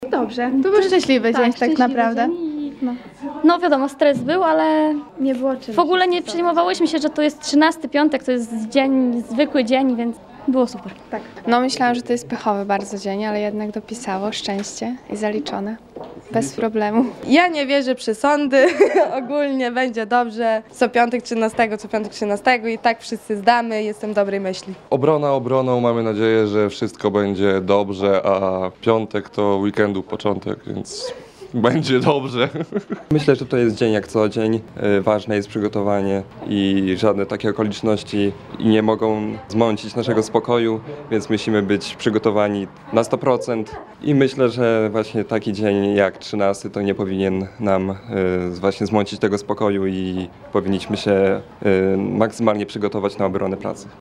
O to, czy piątek 13-tego naprawdę przynosi pecha, zapytaliśmy studentów Państwowej Wyższej Szkoły Zawodowej w Suwałkach, którzy właśnie dziś (13.07) przystąpili do obrony prac dyplomowych.